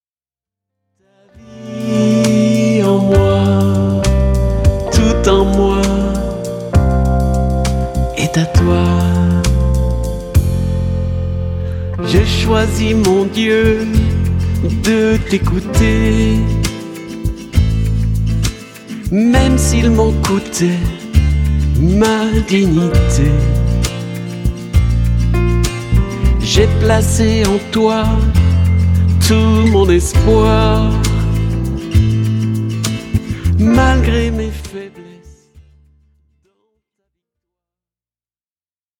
Une pop-Louange actuelle à la fois profonde et dansante